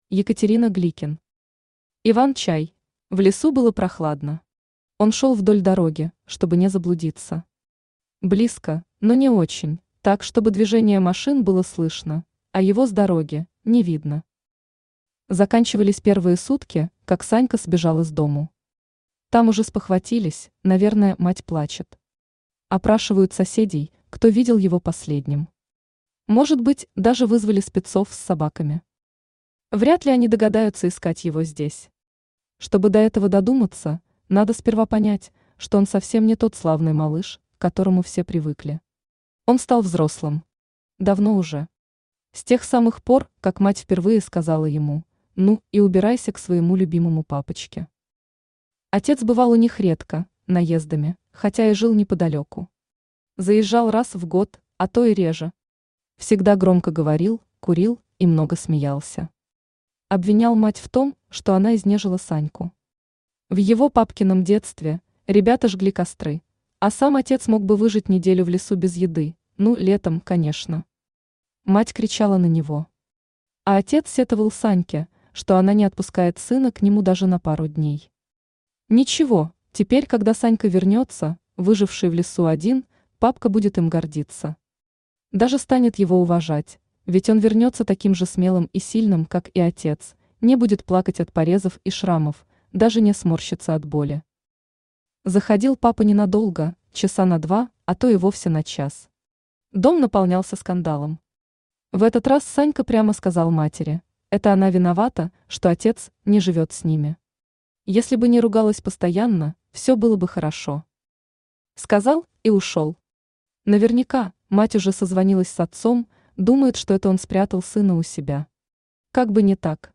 Аудиокнига Иван-чай | Библиотека аудиокниг
Aудиокнига Иван-чай Автор Екатерина Константиновна Гликен Читает аудиокнигу Авточтец ЛитРес.